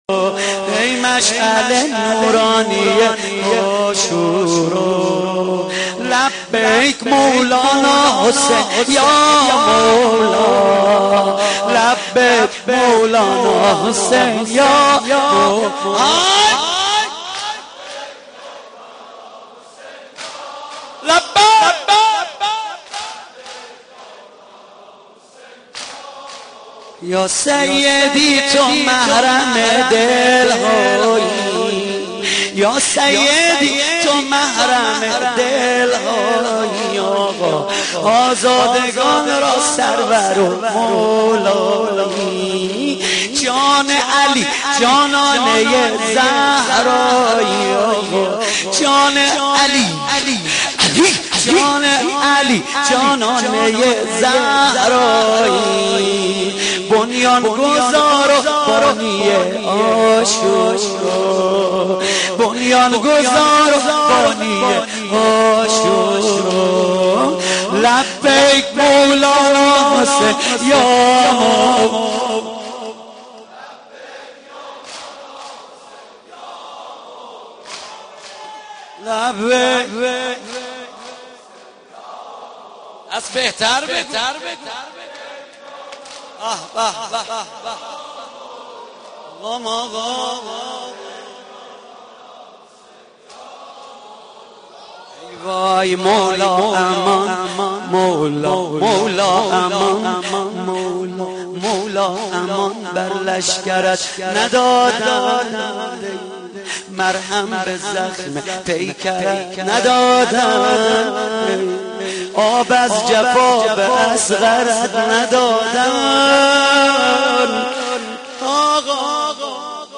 محرم 88 - سینه زنی 3
محرم-88---سینه-زنی-3